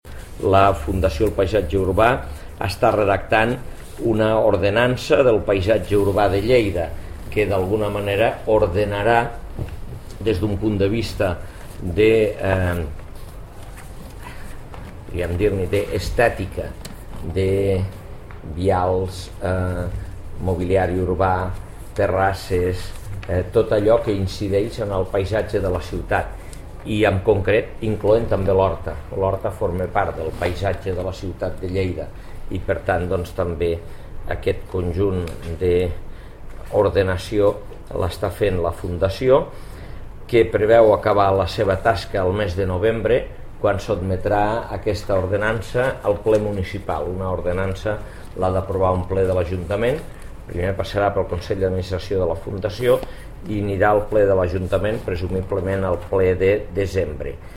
(adjuntem TALL DE VEU Àngel Ros)
tall-de-veu-angel-ros-sobre-lordenanca-del-paisatge-urba